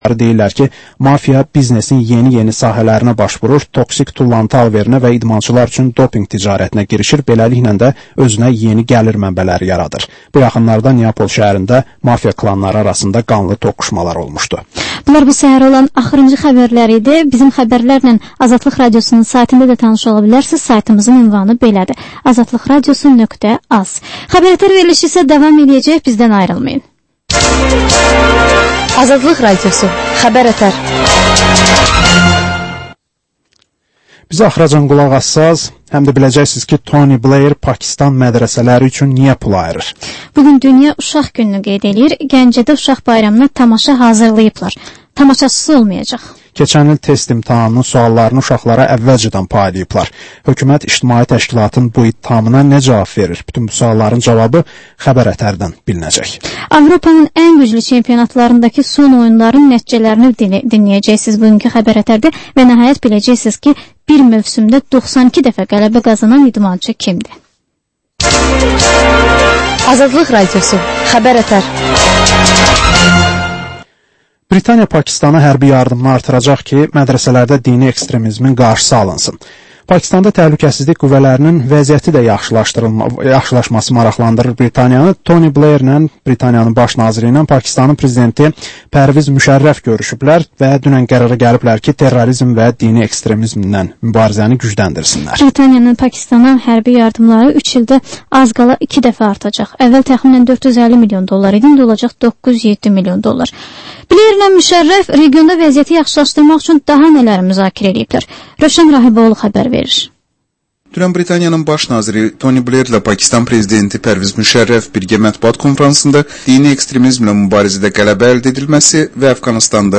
Həftənin mədəniyyər xəbərləri, reportajlar, müsahibələr